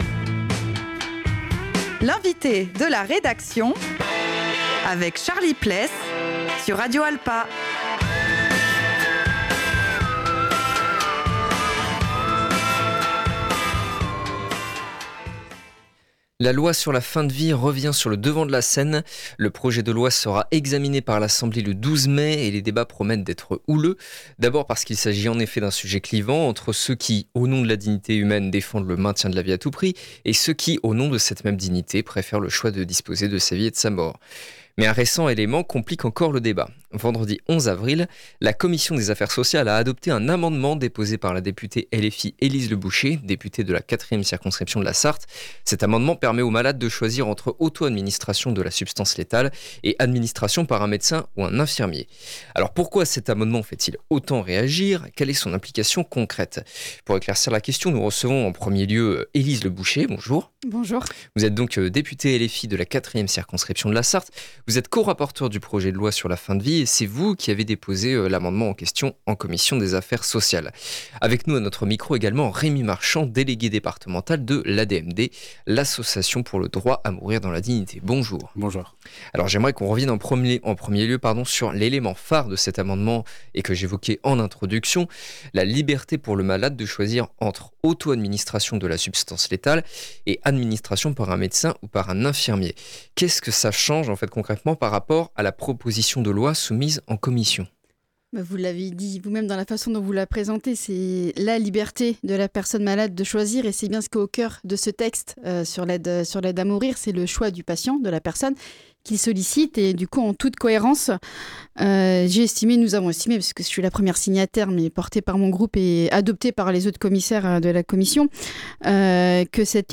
Pour éclaircir la question nous recevons en premier lieu Elise Leboucher, députée LFI de la 4e circonscription de la Sarthe, et co-rapporteure du projet de loi sur la fin de vie.